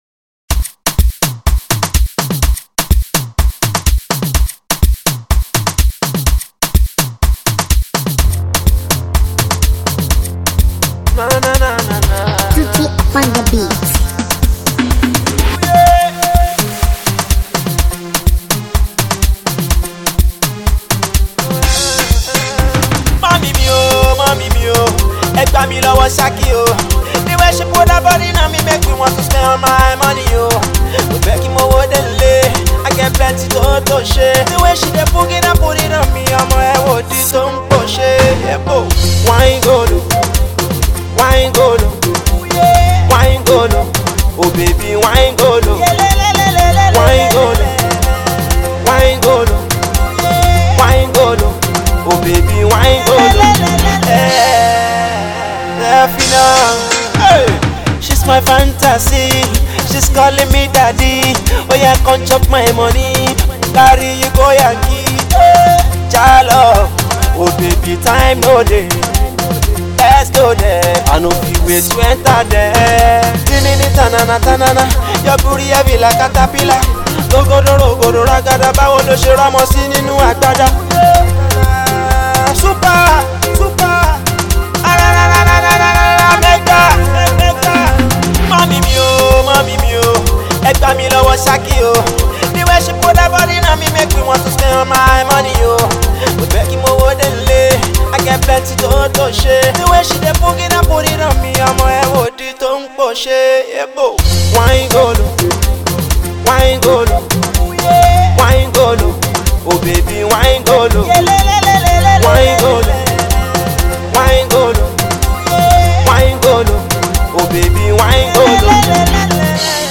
Alternative Pop
pop song